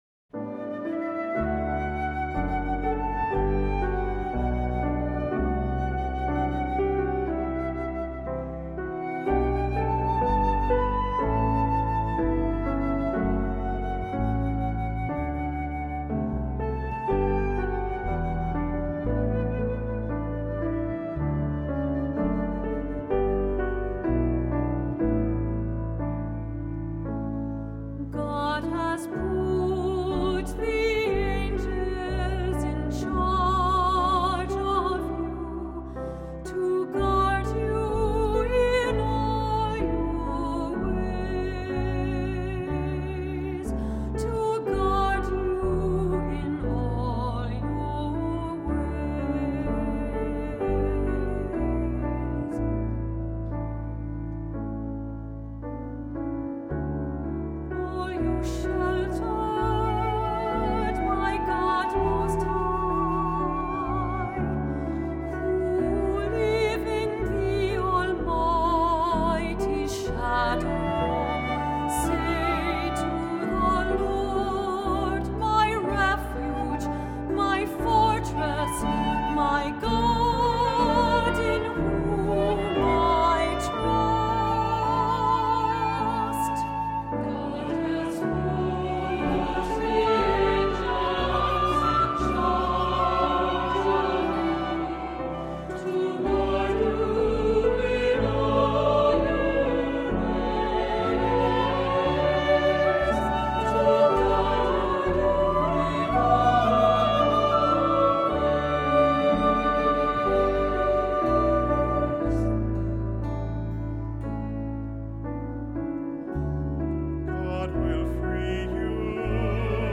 Accompaniment:      Keyboard, C Instrument I;C Instrument II
Music Category:      Christian
Choir or cantor.